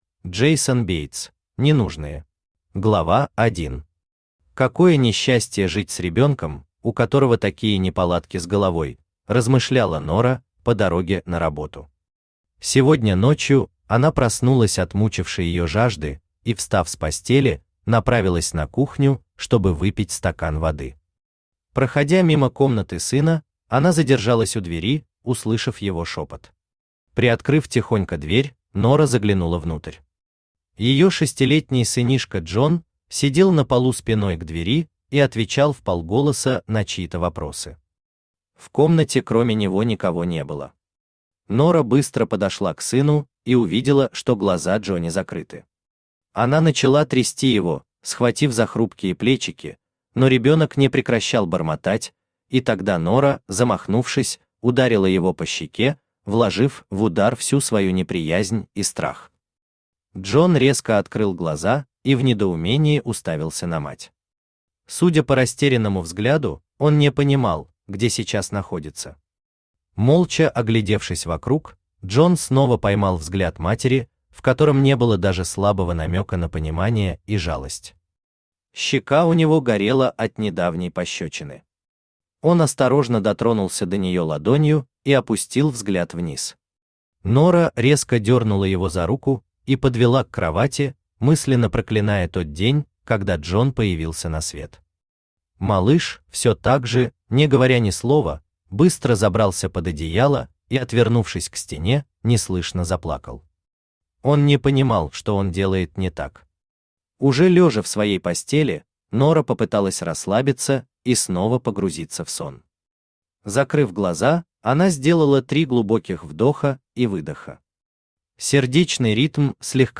Аудиокнига Ненужные | Библиотека аудиокниг
Aудиокнига Ненужные Автор Джейсон Бейтс Читает аудиокнигу Авточтец ЛитРес.